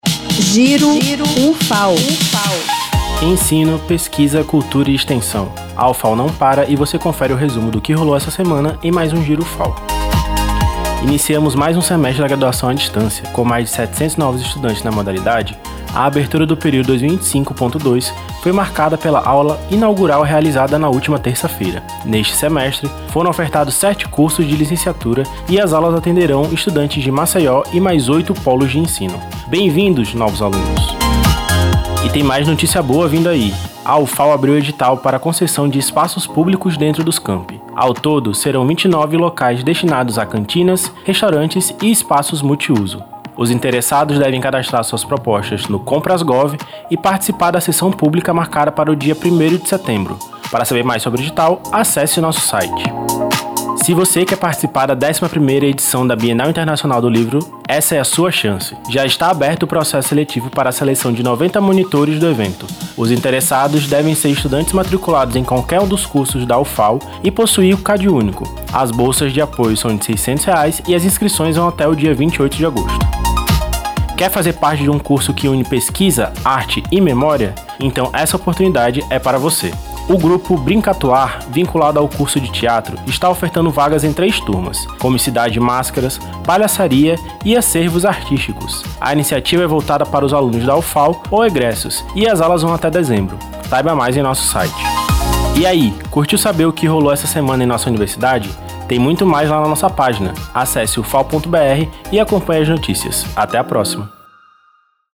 Roteiro e apresentação